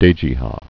(dājē-hä)